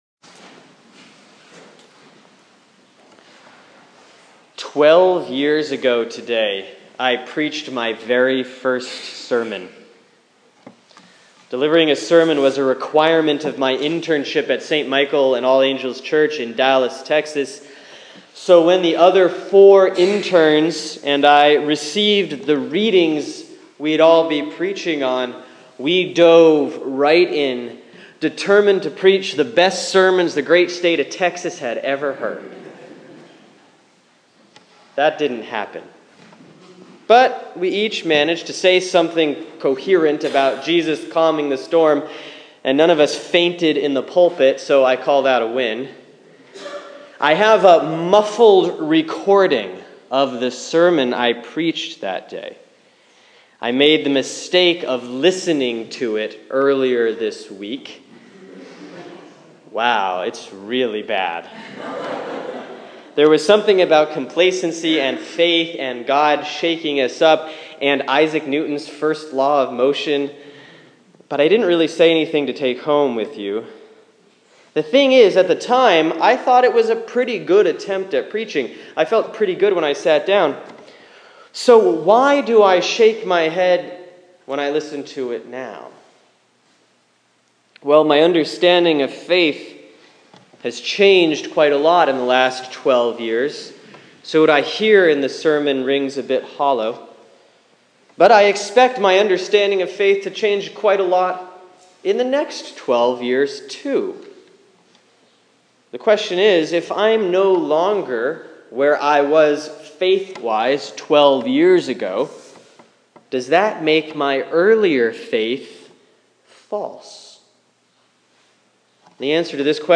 Sermon for Sunday, June 21, 2015 || Proper 7B || Mark 4:35-41